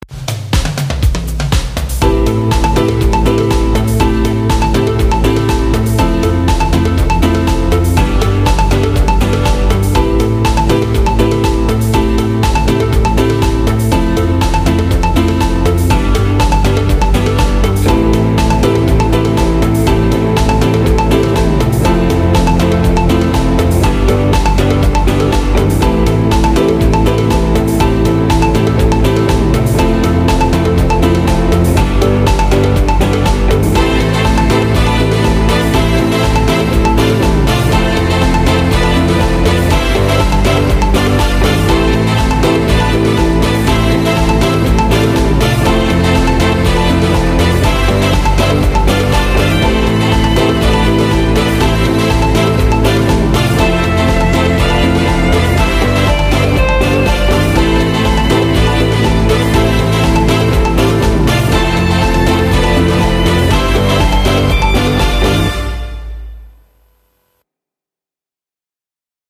SD-50を使った音楽製作の練習。
078と080は、とりあえずフレーズループをしてるだけの、展開のない曲ばかりだ。
ちなみに、080はギターを打ち込みではなく録音をしている。